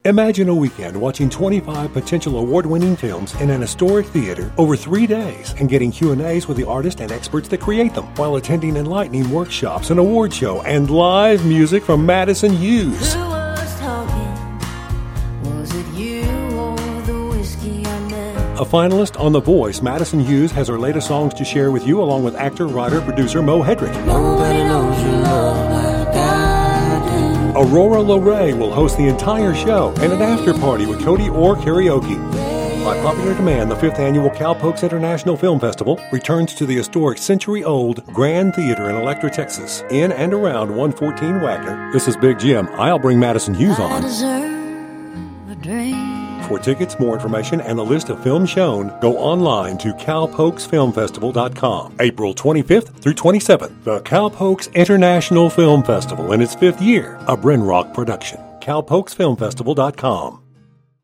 99.9 KLUR RADIO AD